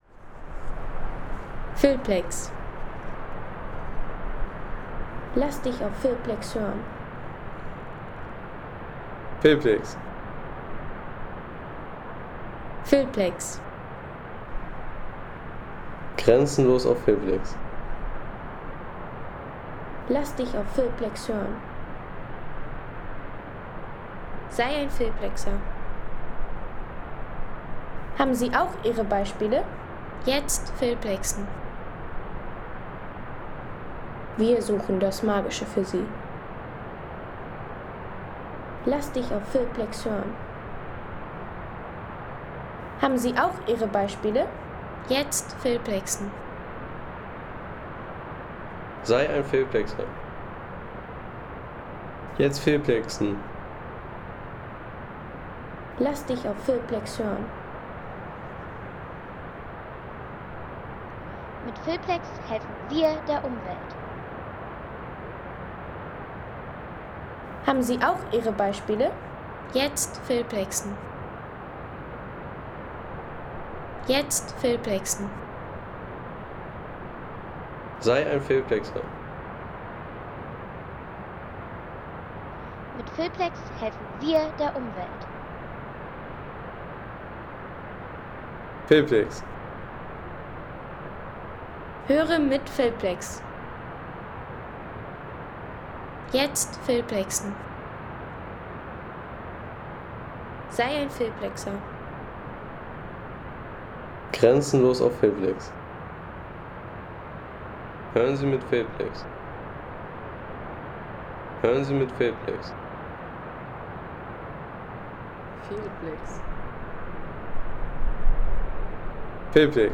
Innerbergtal - Wasserfälle
Magische Momente auf dem „Vier Seen Weg“ – Natur pur auf 2600 Me ... 3,50 € Inkl. 19% MwSt.